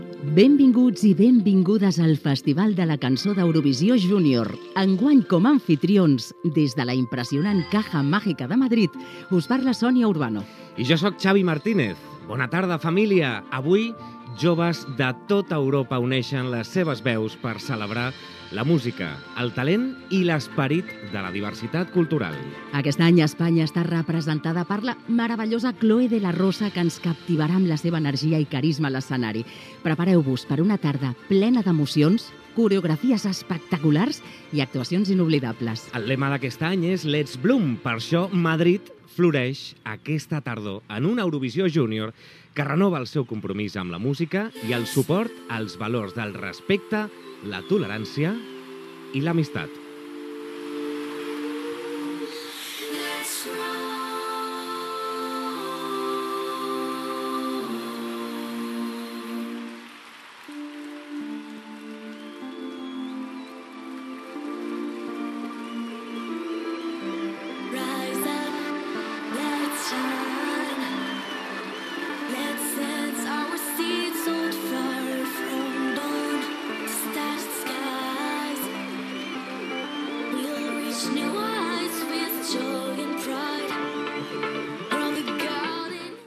Inici de la transmissió del festival europeu de la cançó des de la Caja Mágica de Madrid
Musical